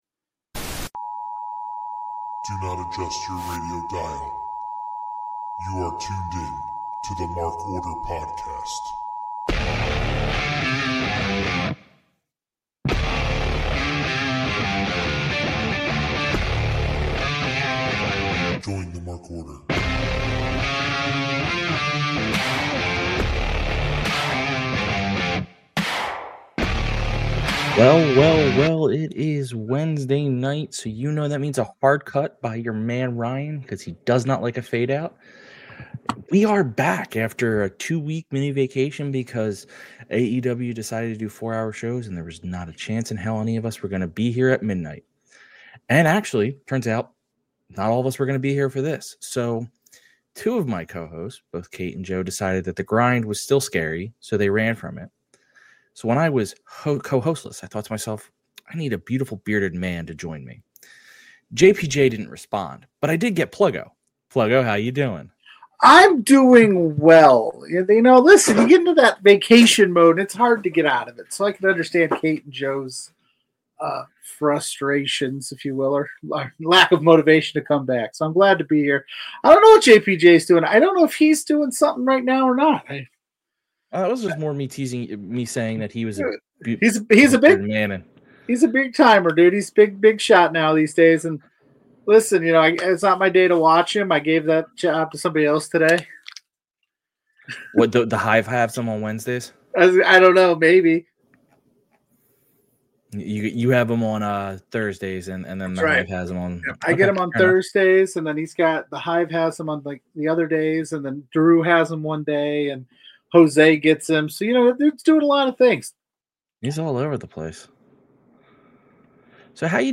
The two fellas talk about another fun Dynamite.